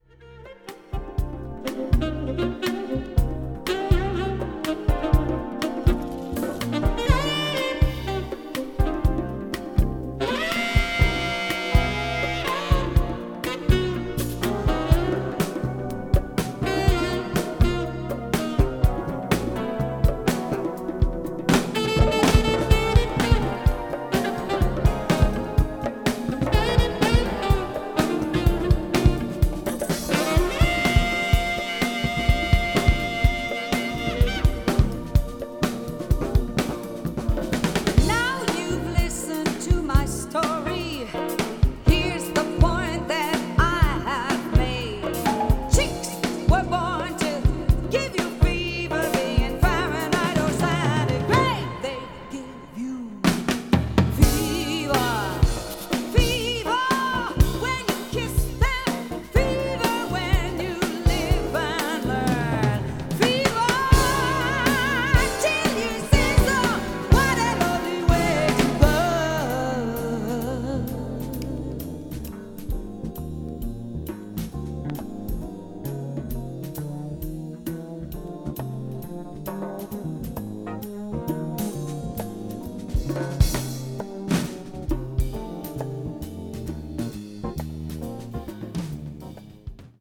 media : EX/EX(わずかにチリノイズが入る箇所あり)
chanson   contemporary jazz   french pop   jazz vocal